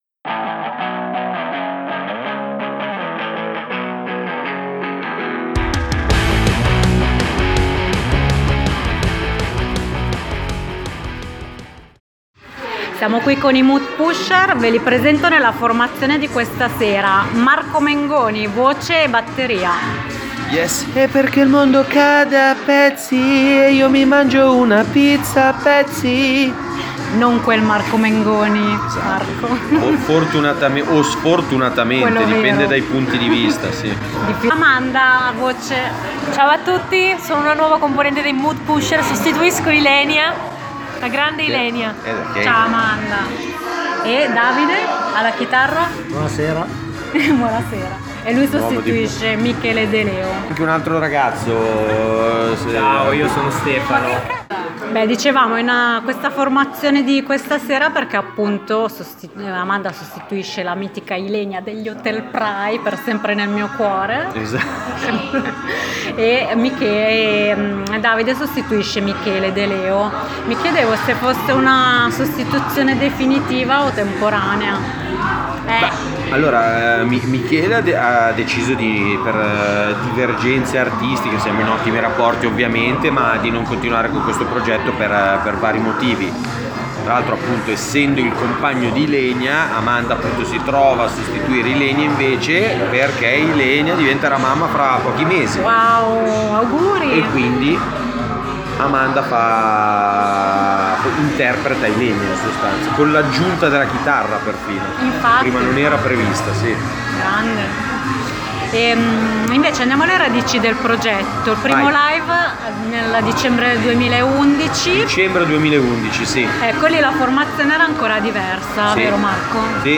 In occasione del loro ultimo live, abbiamo fatto una bella chiacchierata con i Mood Pusher, band varesina composta da:
Intervista_Mood_Pusher.mp3